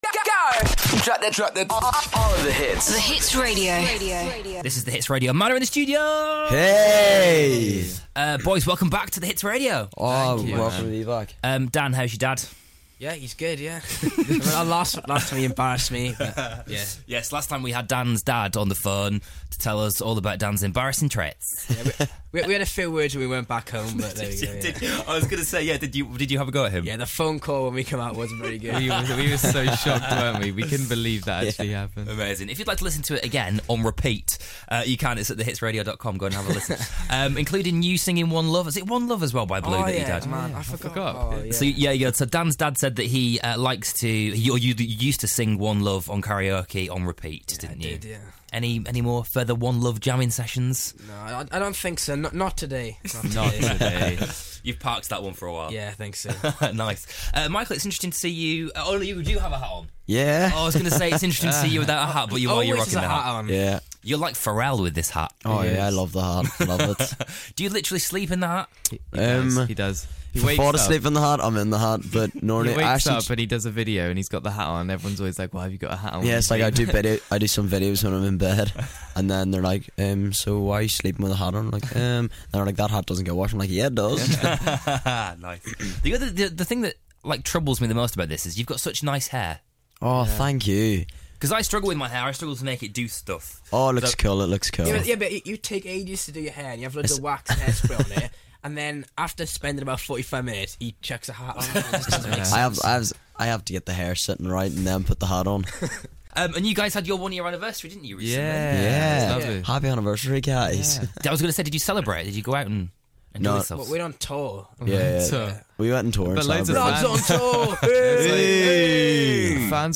The M.A.D boys joined us in the studio to chat about touring capers. They even threw in an acoustic set too!